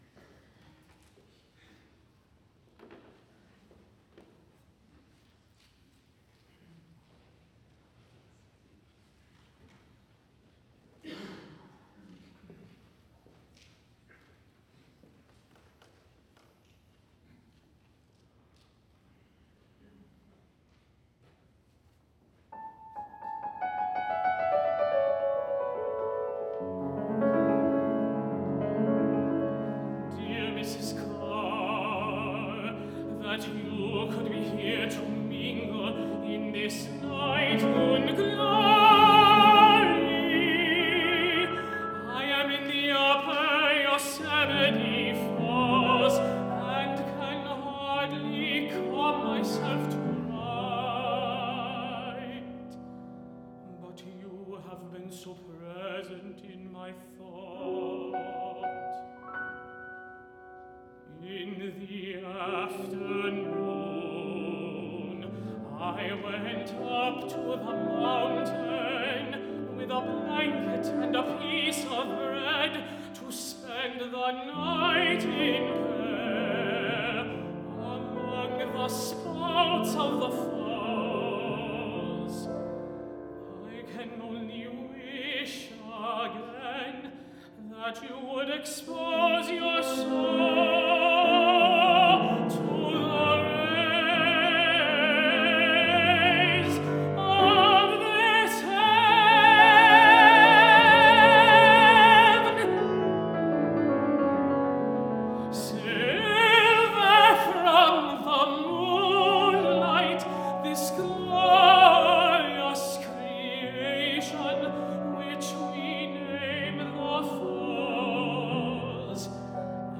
for High Voice and Piano (2014)